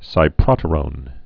(sī-prŏtə-rōn)